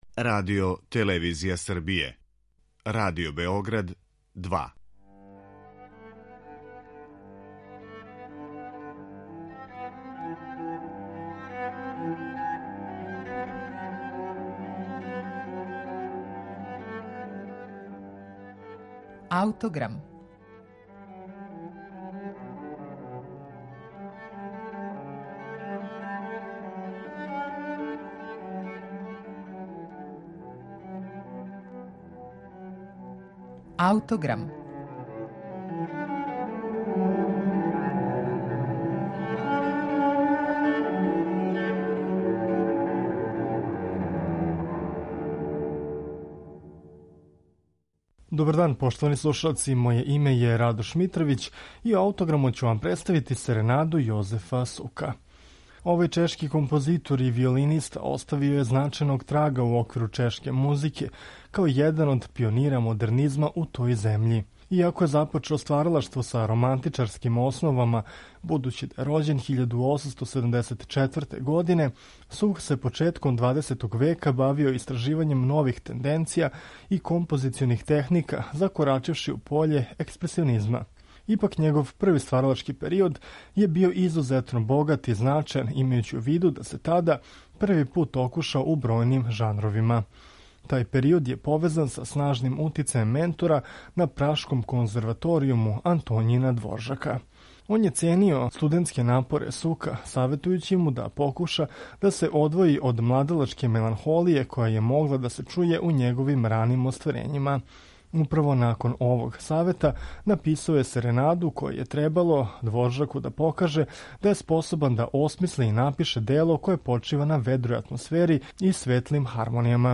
Дело изводи Дански камерни оркестар младих.